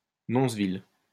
Nonzeville (French pronunciation: [nɔ̃zvil]